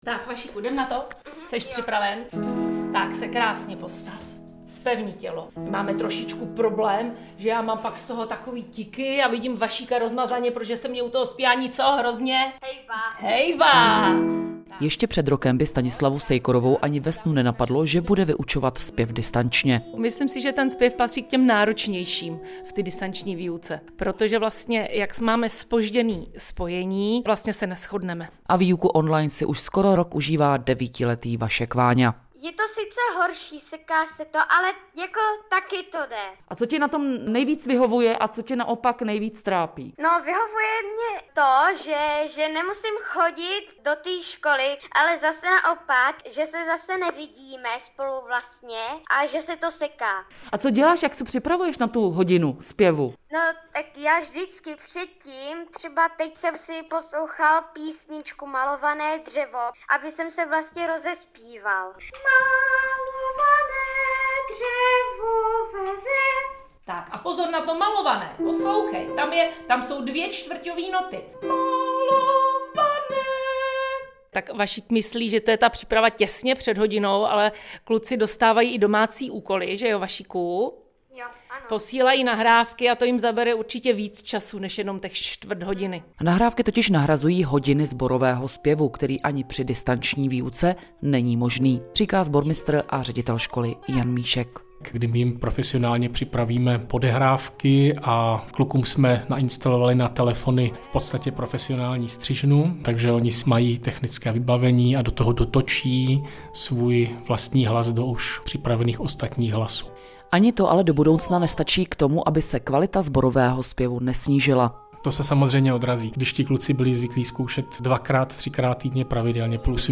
Reportáž Českého rozhlasu Pardubice z distanční výuky